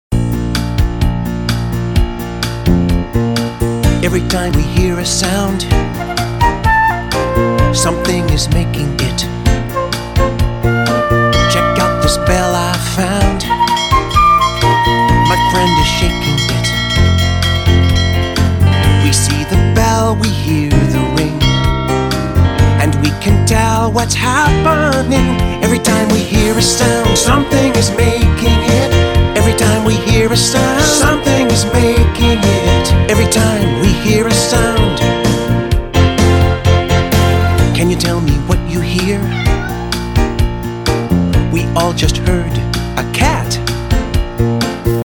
kid funk, blues, pop, rock, country, anthem and ballad